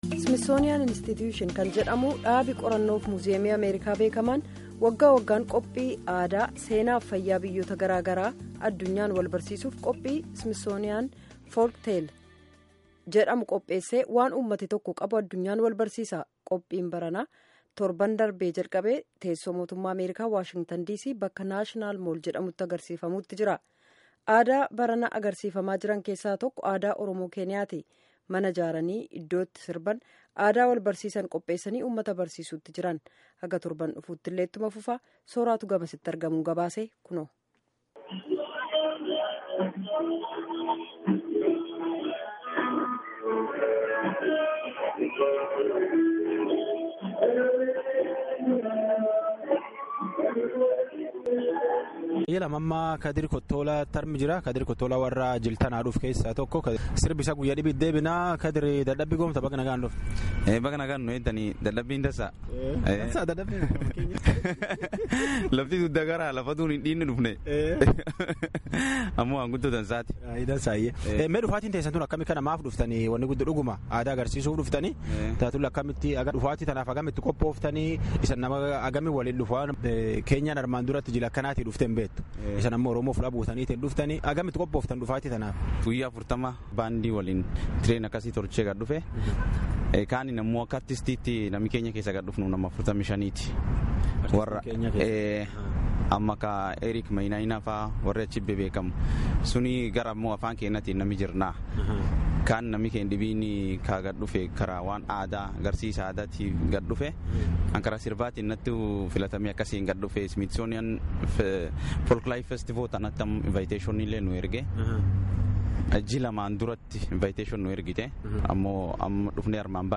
Boorana Keenyaa dhufetti dirree National Mall Washington DC jirtutti sribaa faaruun akkana aadaa bariisa.